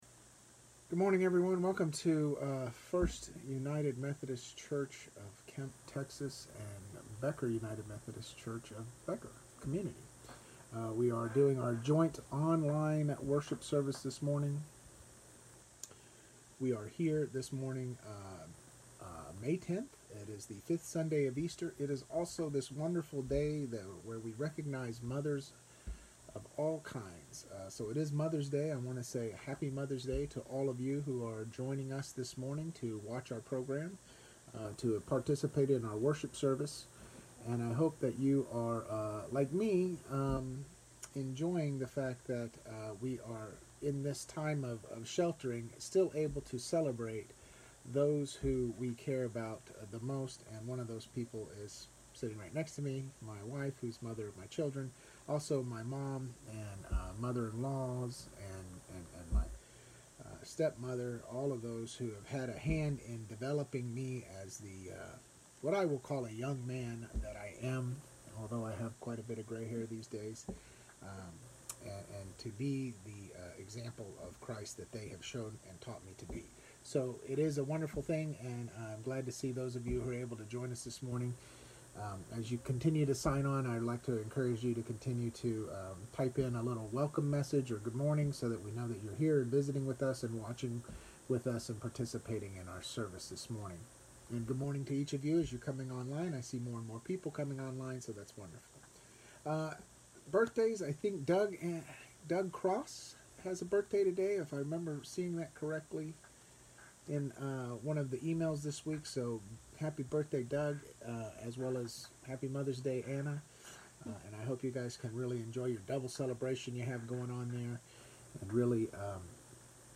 First United Methodist Church Kemp Sermons 2020